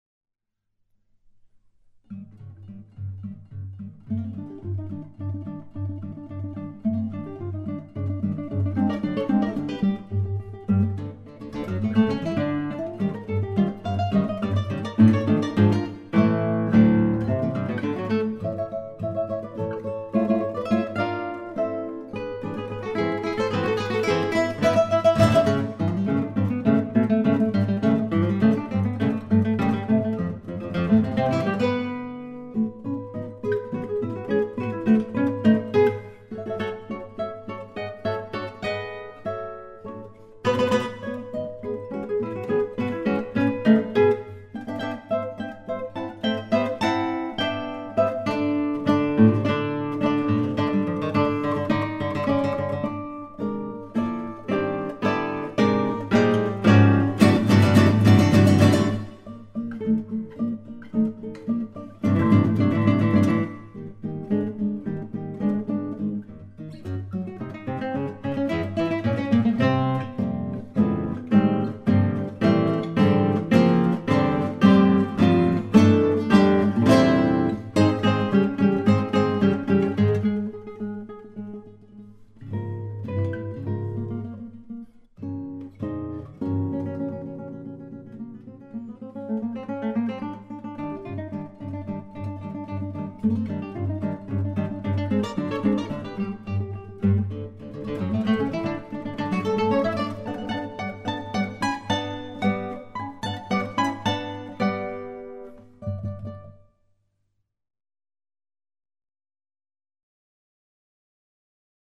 Guitar Trio